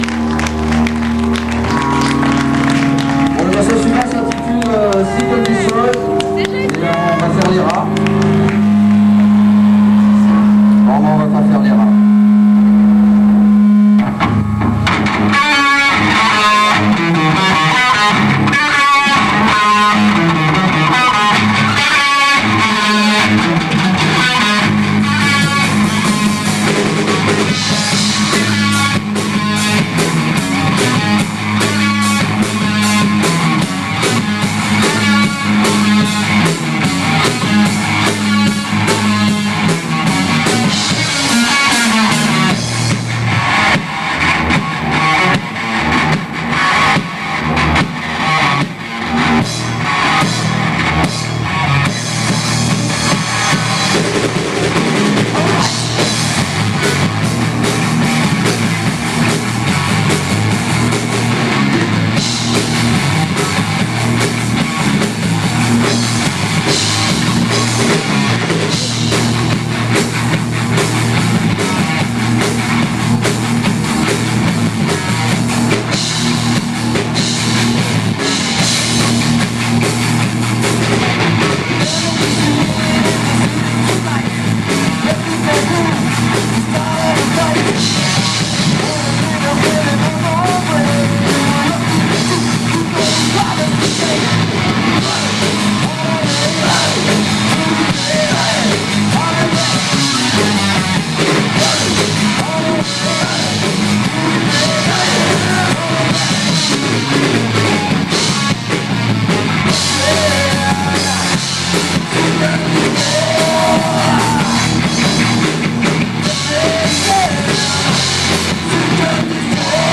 Live